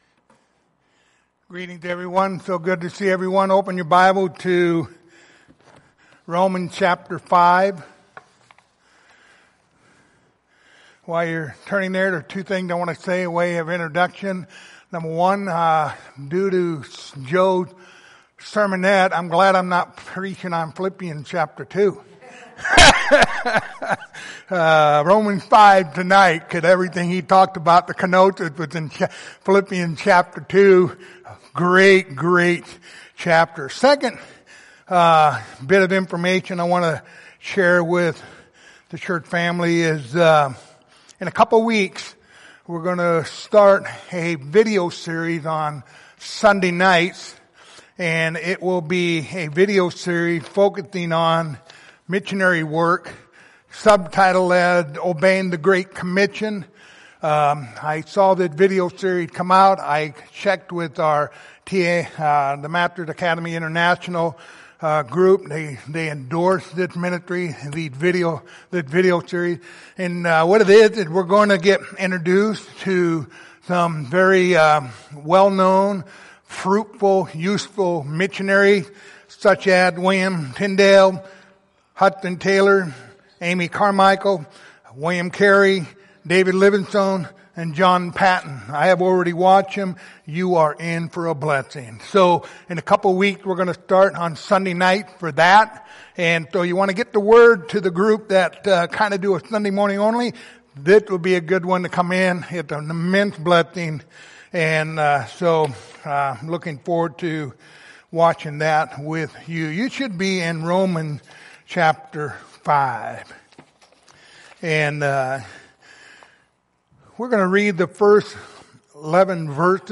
Lord's Supper Passage: Romans 5:1-11 Service Type: Lord's Supper Topics